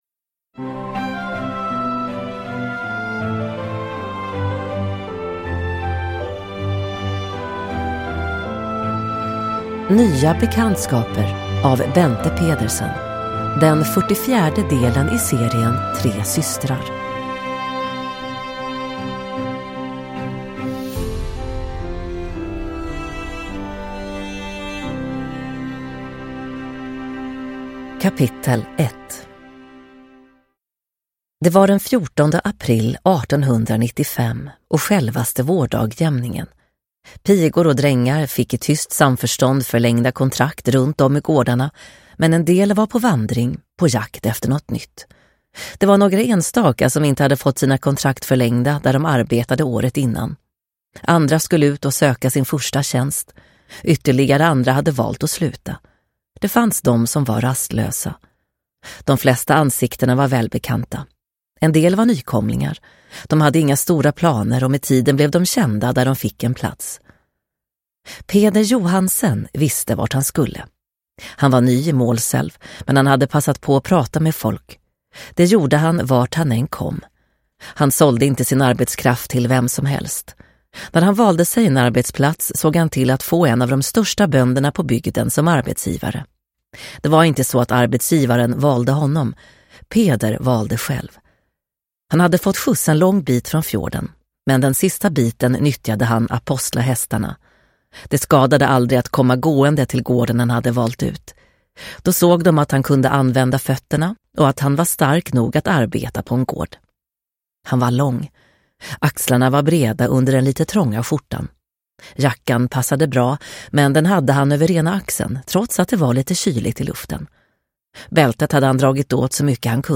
Nya bekantskaper – Ljudbok – Laddas ner